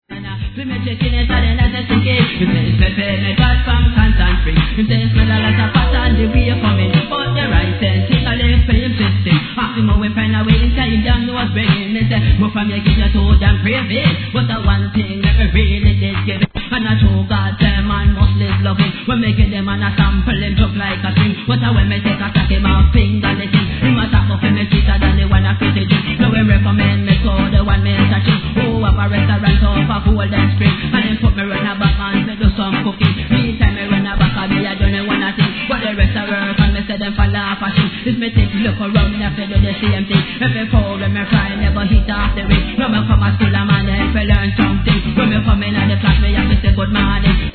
一瞬音が途切れる感あります
REGGAE